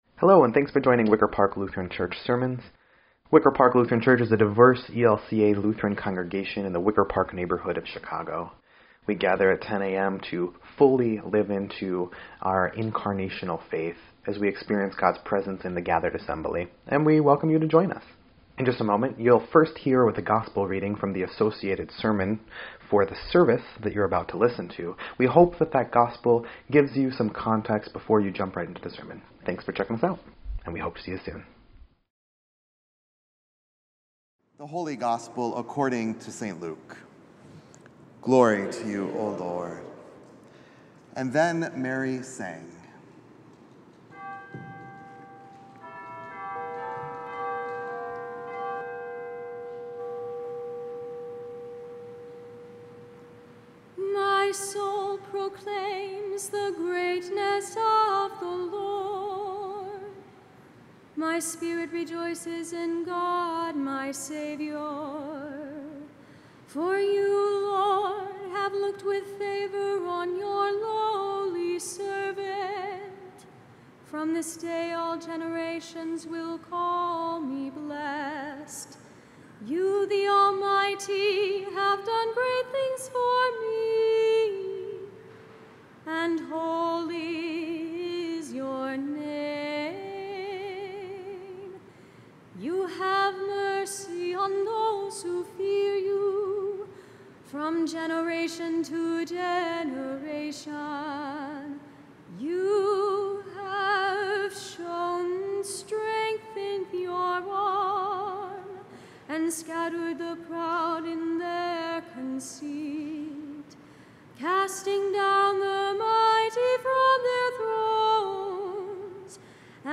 8.18.24-Sermon_EDIT.mp3